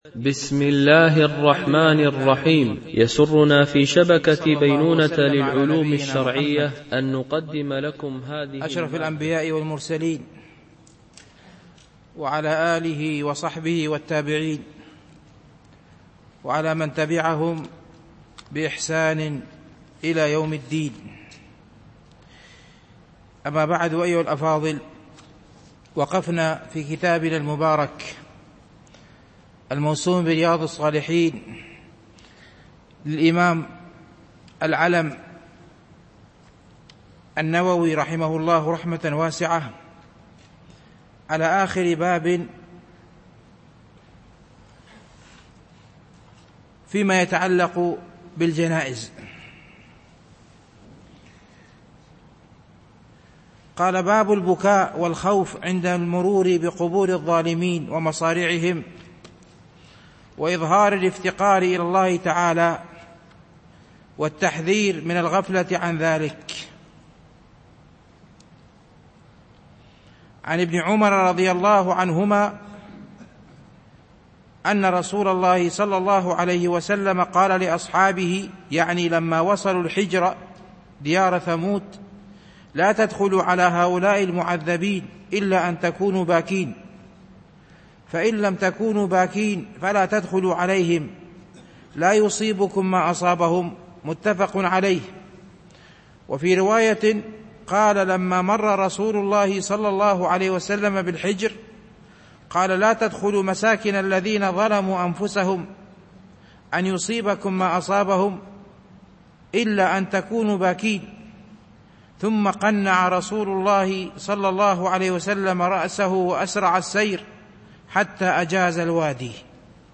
شرح رياض الصالحين – الدرس 250 ( الحديث 962 – 968 )
التنسيق: MP3 Mono 22kHz 32Kbps (CBR)